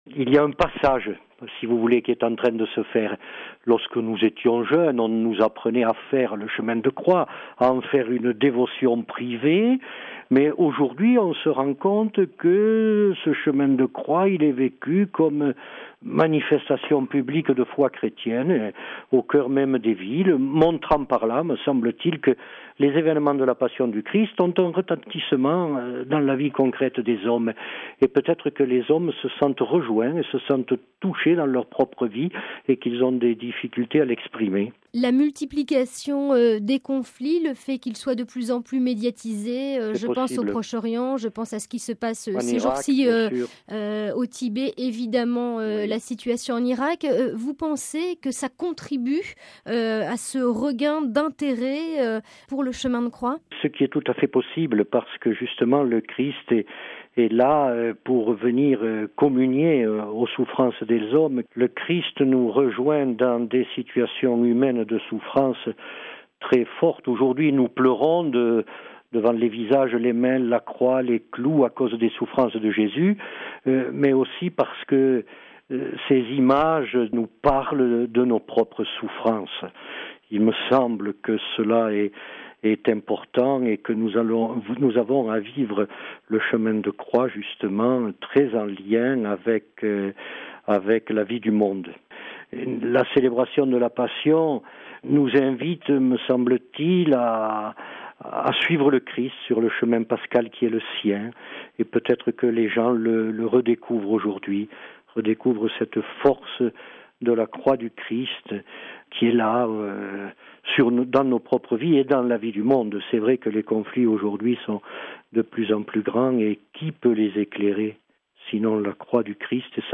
Le commentaire de l’évêque de Périgueux, Mgr Michel Mouïsse RealAudio